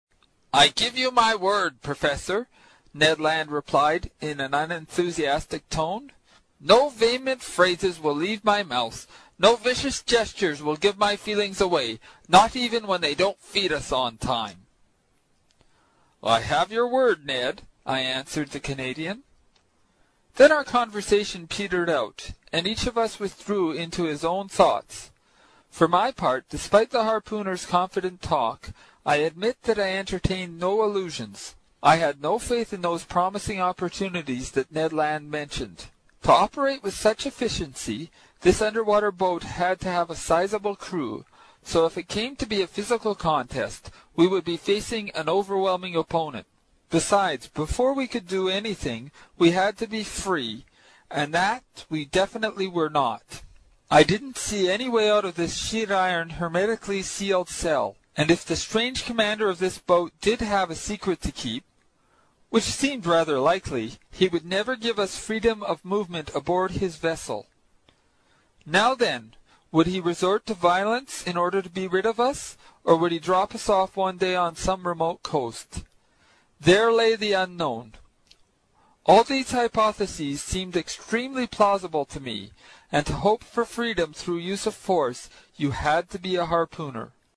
在线英语听力室英语听书《海底两万里》第126期 第9章 尼德兰的愤怒(11)的听力文件下载,《海底两万里》中英双语有声读物附MP3下载